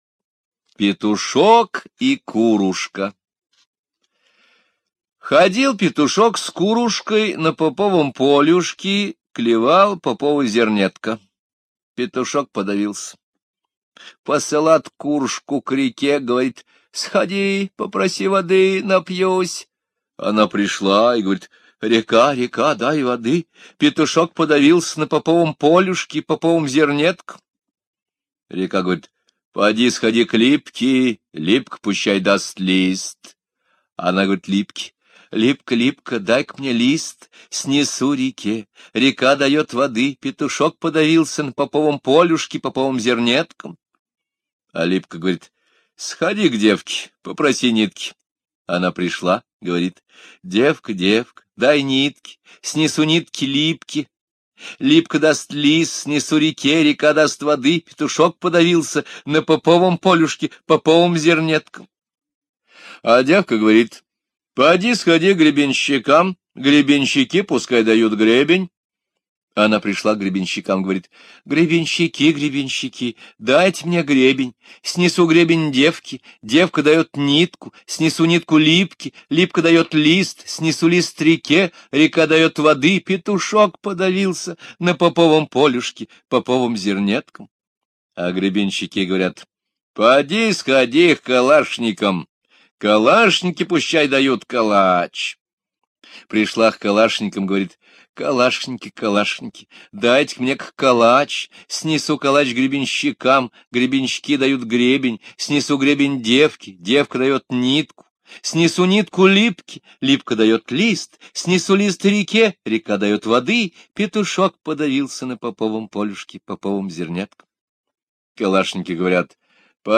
Петушок и курушка - русская народная аудиосказка. Короткая сказка о курочке, которая спасла жизнь своему другу петушку.